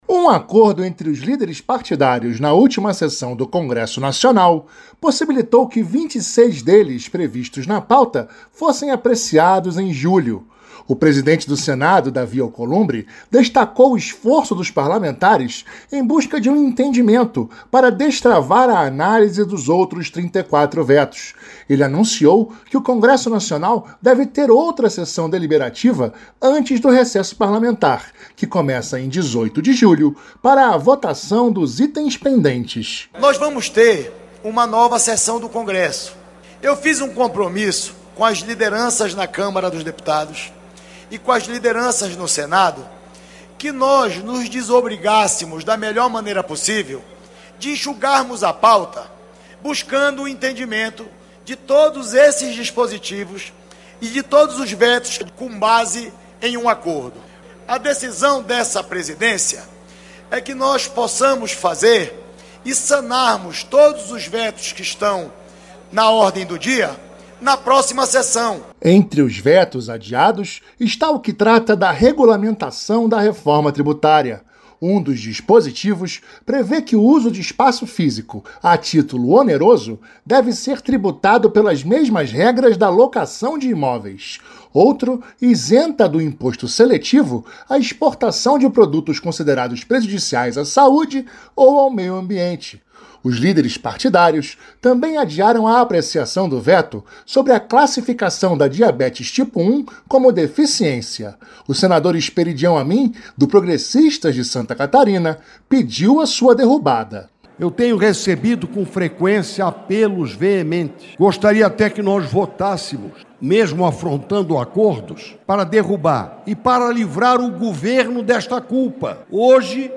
Senador Davi Alcolumbre
Senador Esperidião Amin